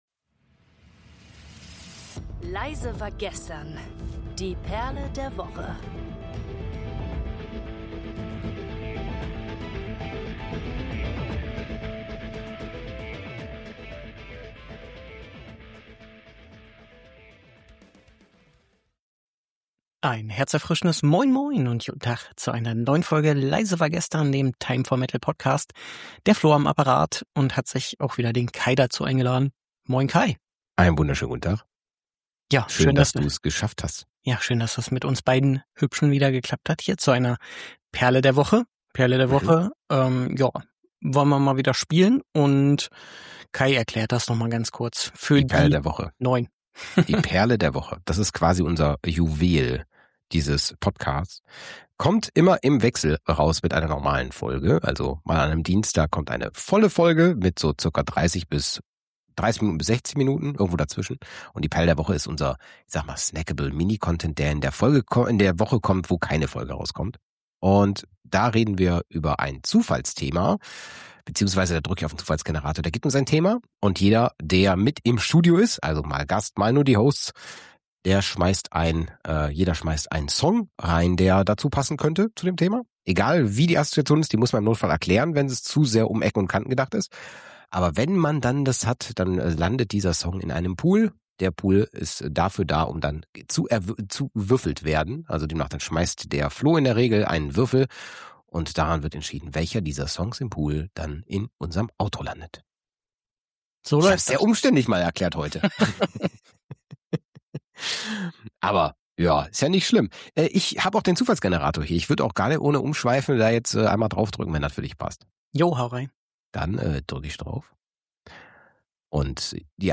Nirvana, Nevermore, Pearl Jam und The Fall of Troy stehen im Mittelpunkt, während die Hosts ihre ganz persönlichen Erinnerungen und Anekdoten zu diesen stilprägenden Bands teilen.